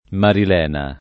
vai all'elenco alfabetico delle voci ingrandisci il carattere 100% rimpicciolisci il carattere stampa invia tramite posta elettronica codividi su Facebook Marilena [ maril $ na ] pers. f. (= Maria Lena) — pn. dell’ -e- non più legata a quella dell’ -e- di Lena , essendo poco sentita la derivazione